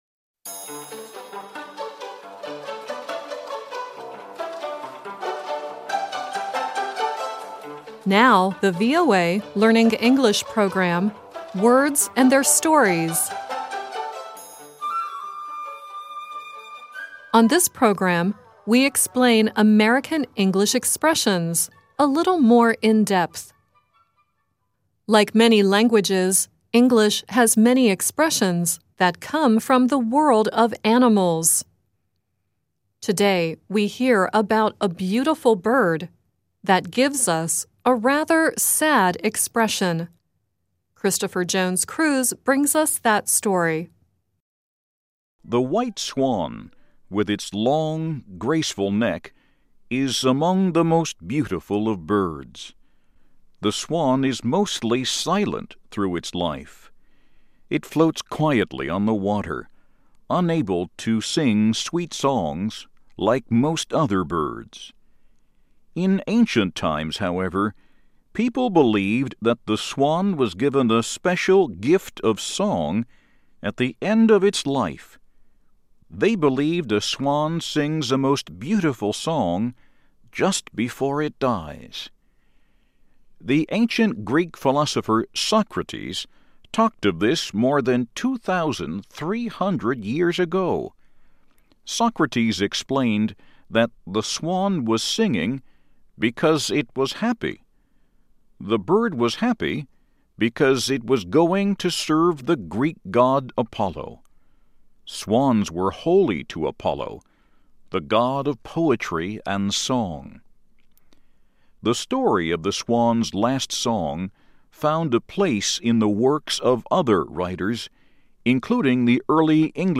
The song at the end is Dua Lipa singing “Swan Song.”